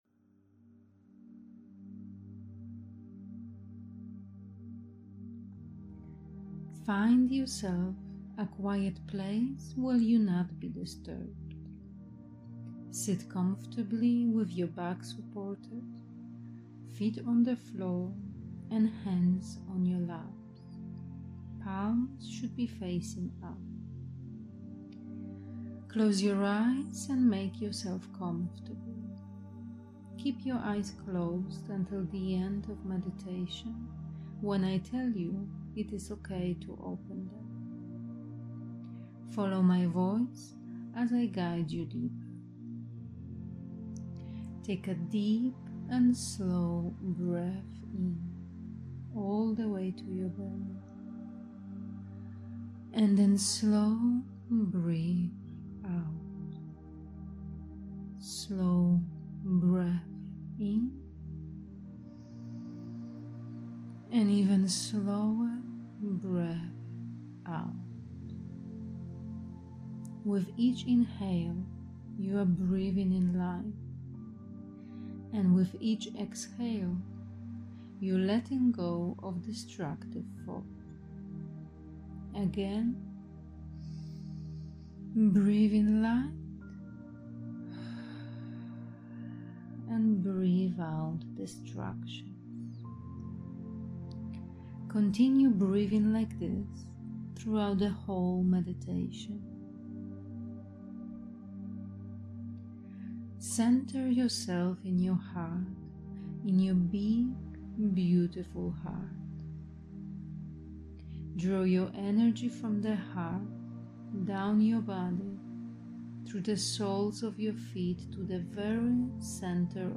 A Theta Healing guided meditation changing the limiting beliefs, and negative programs about money.